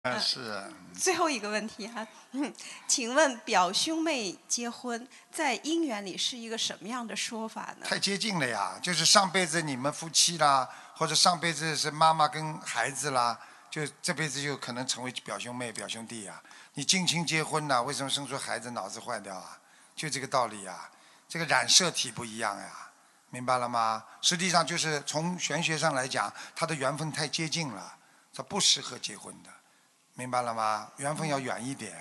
Murid Bertanya Master Menjawab — Seminar Dharma Amerika Utara Tahun 2018